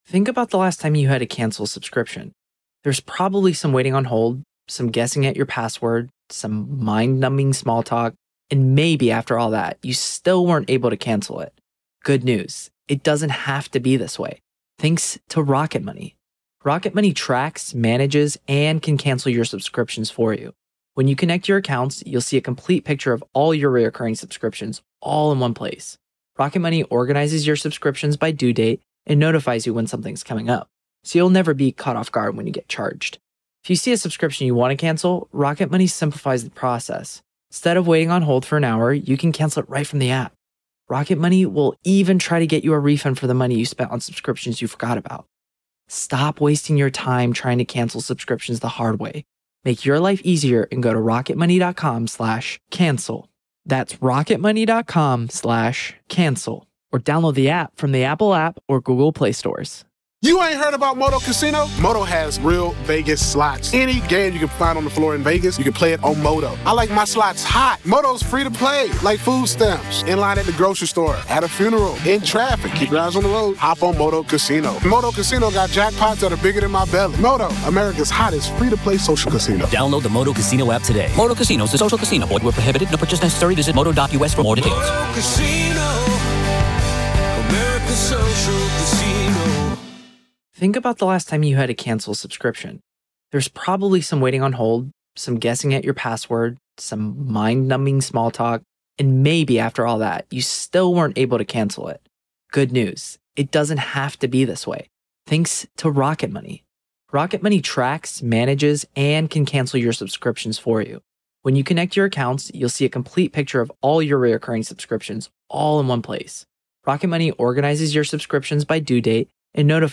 LIVE COURTROOM COVERAGE — NO COMMENTARY
This series provides unfiltered access to the testimony, exhibits, expert witnesses, and courtroom decisions as they happen. There is no editorializing, no added narration, and no commentary — just the court, the attorneys, the witnesses, and the judge.